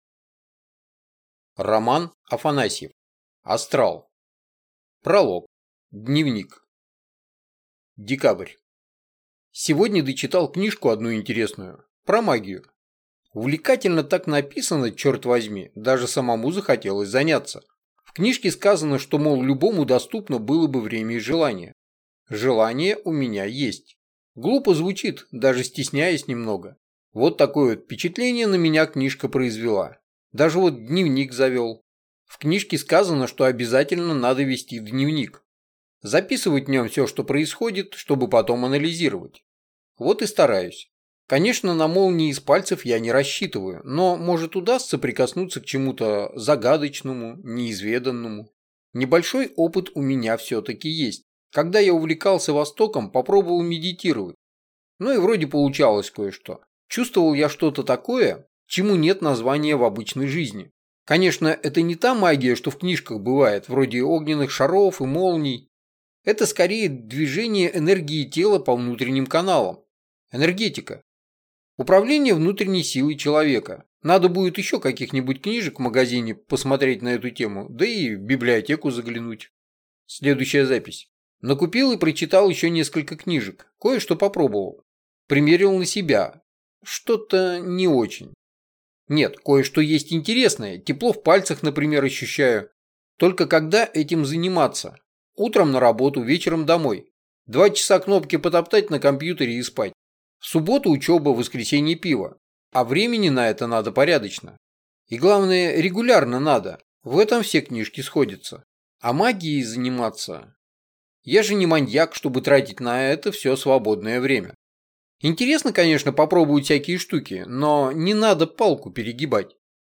Аудиокнига Астрал | Библиотека аудиокниг